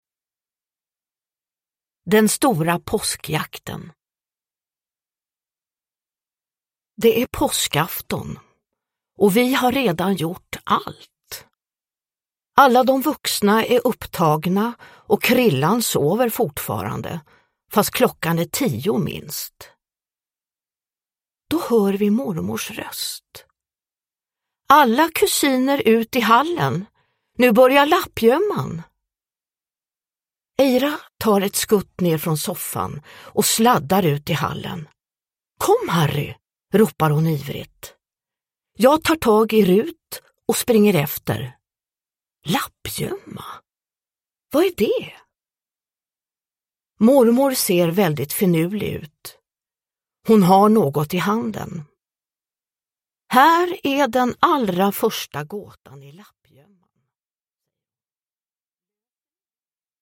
Den stora påskjakten – Ljudbok